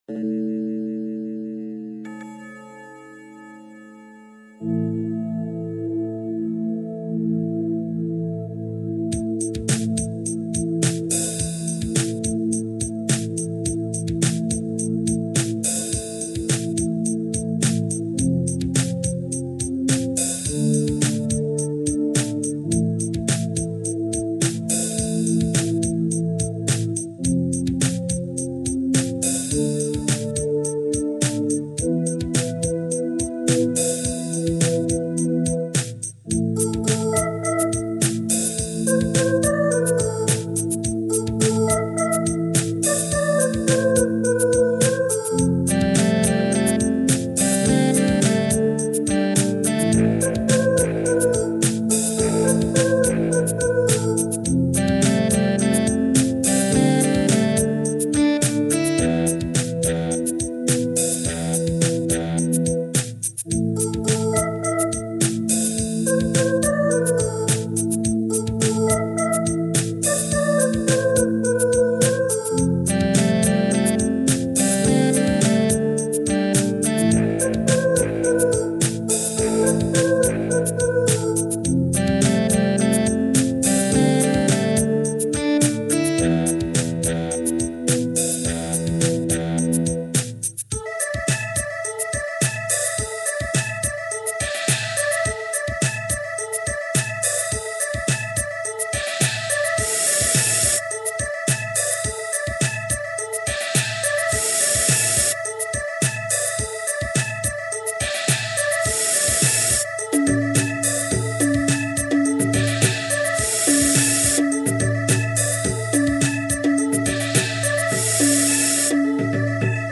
Voici donc la version MP3 du vieux fichier MIDI de 2002:
Attention, c'est kitsch.